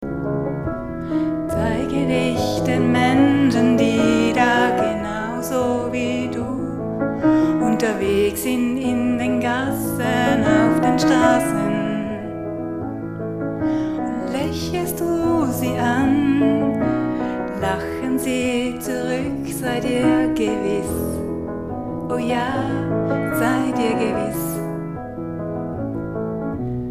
Poesie
Momentum-Aufnahmen